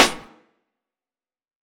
Dilla Snare Hard.wav